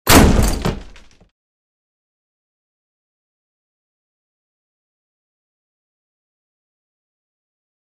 Heavy Wood Door Slams Hard With Debris Fall And Creaks